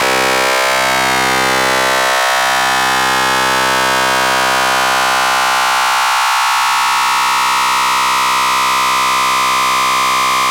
VCO ENV FM 2.wav